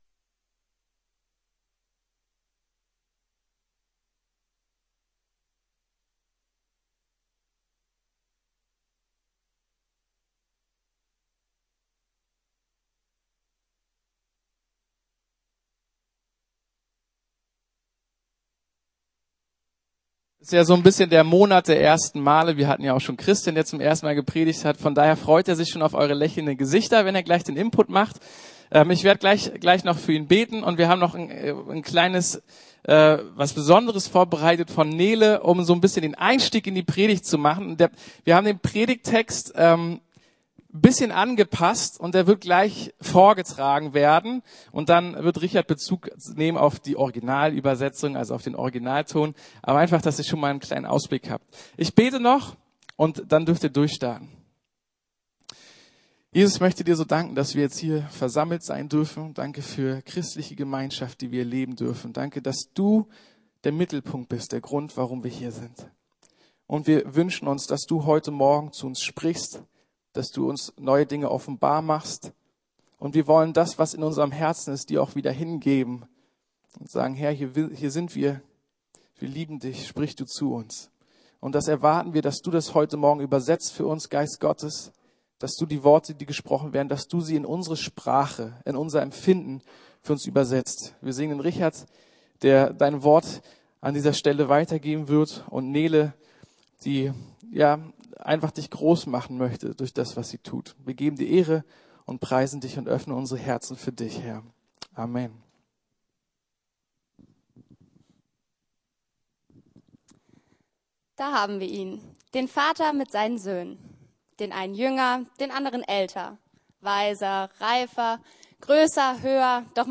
Erinnere dich! ~ Predigten der LUKAS GEMEINDE Podcast